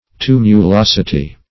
Search Result for " tumulosity" : The Collaborative International Dictionary of English v.0.48: Tumulosity \Tu`mu*los"i*ty\, n. The quality or state of being tumulous; hilliness.